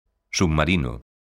submarino_son.mp3